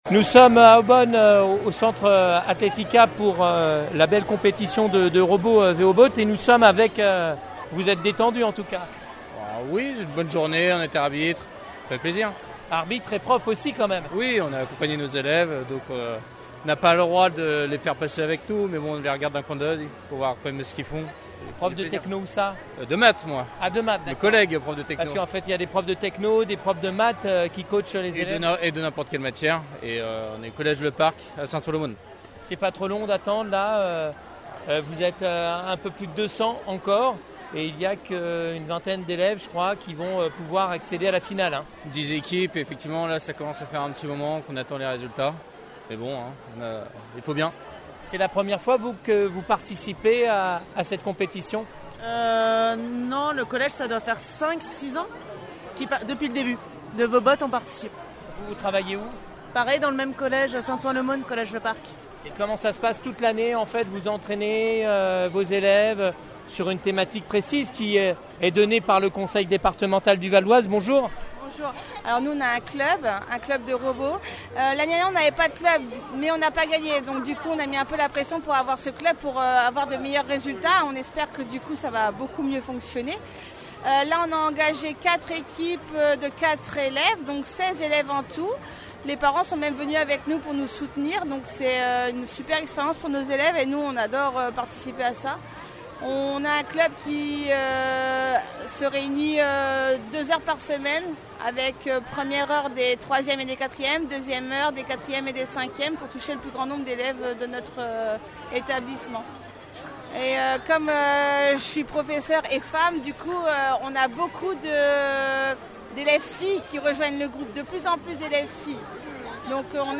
Reportage sur place :